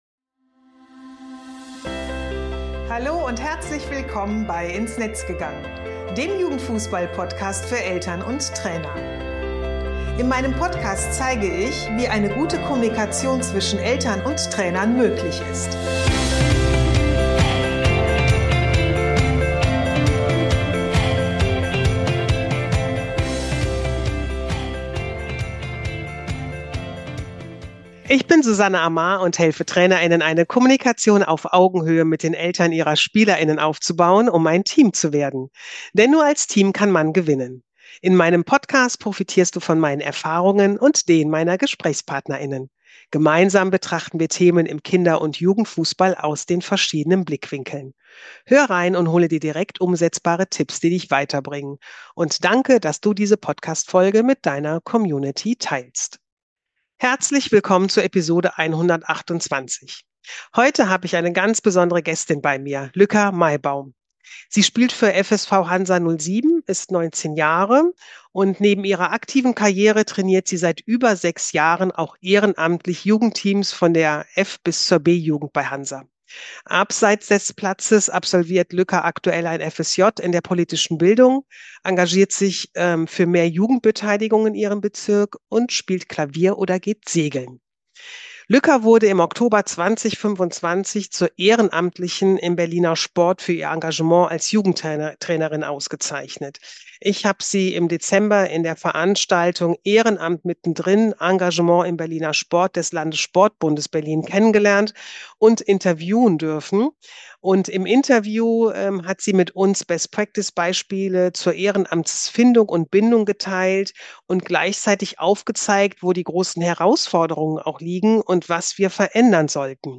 Im Gespräch erzählt sie, wie sie ins Ehrenamt hineingewachsen ist, was sie motiviert dranzubleiben und welche Veränderungen Vereine brauchen, damit sich auch in Zukunft genug Menschen engagieren. Wir sprechen darüber, warum immer mehr Kinder Fußball spielen wollen, Vereine aber gleichzeitig Schwierigkeiten haben, genügend Ehrenamtliche zu finden.